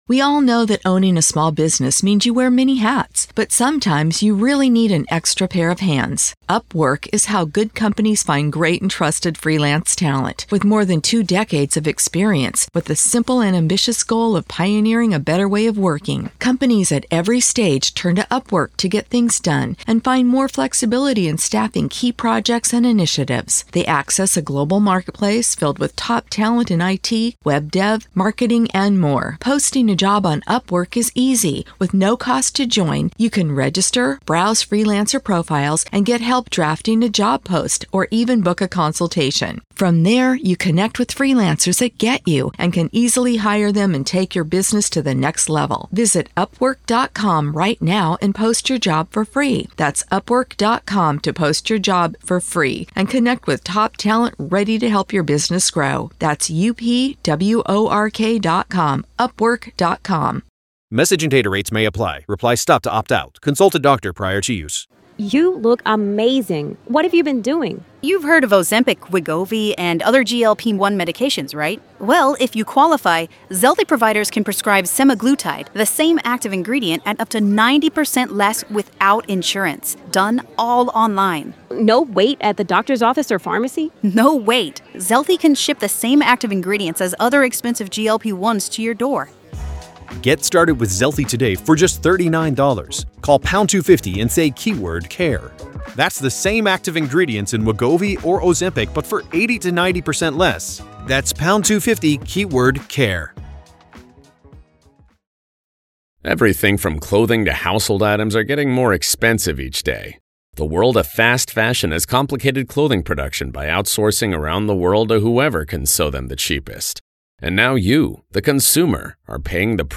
Howard Blum Breaks Down Everything He Now Believes About The Bryan Kohberger Case – Full Interview